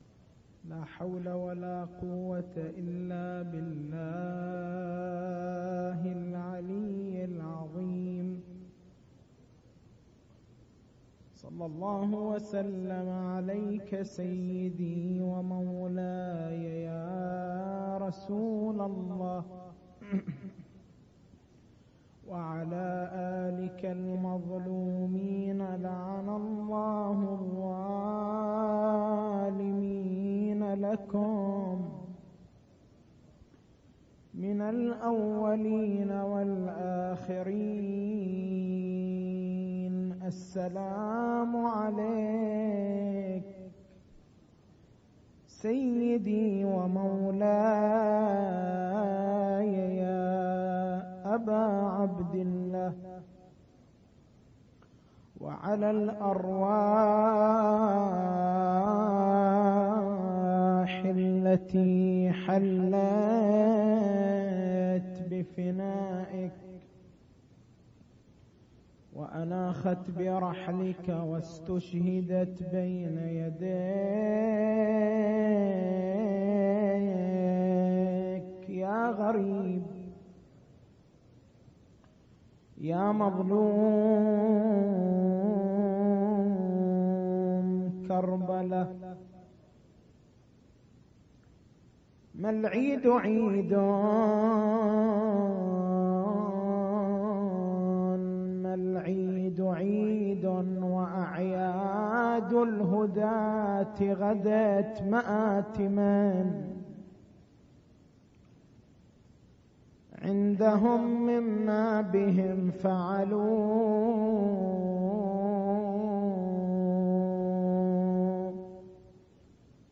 مكتبة المحاضرات